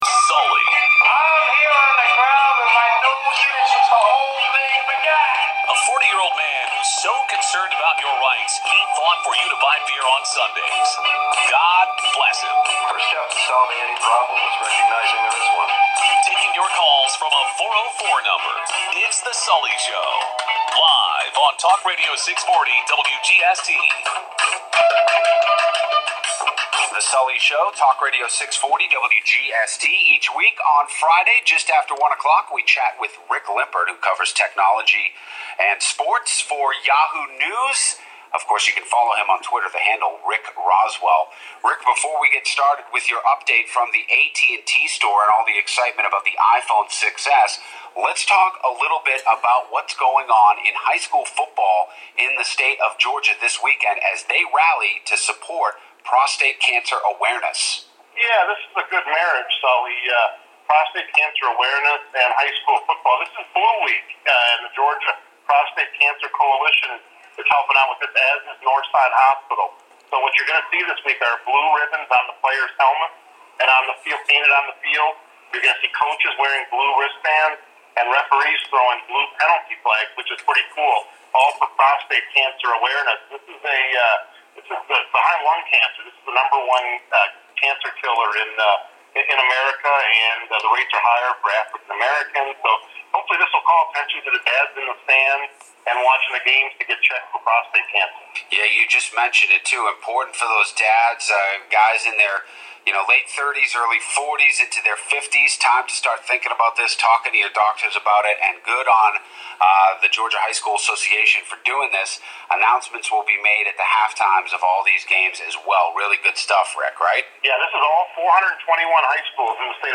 My Segment on "The Sully Show" on 640 am WGST for 9/25/15